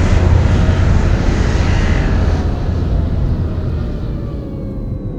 landing.wav